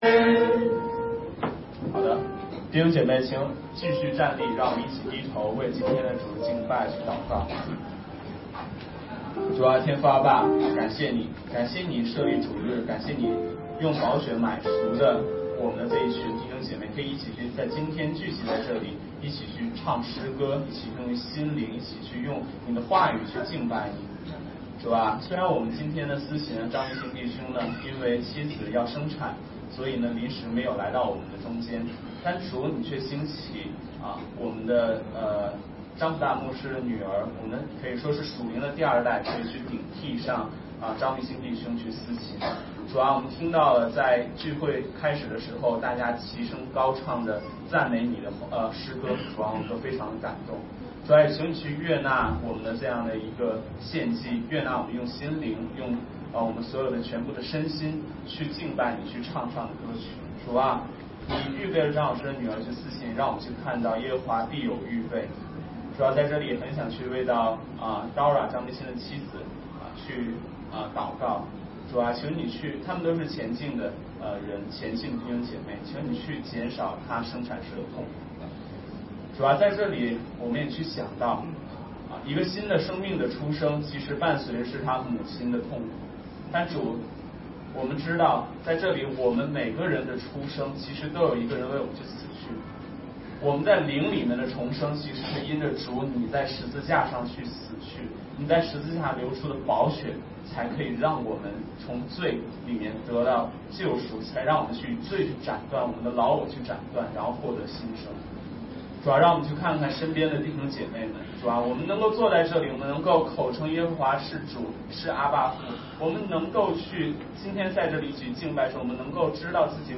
雅各书圣经讲道